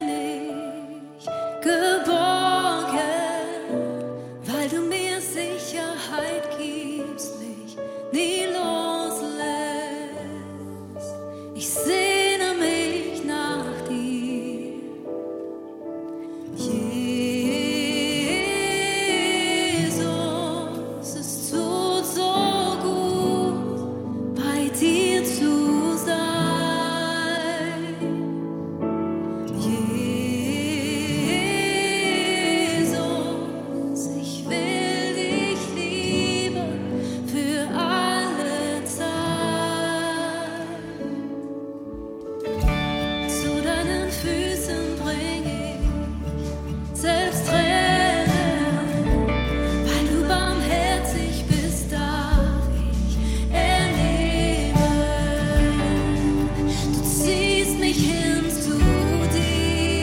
Lobpreis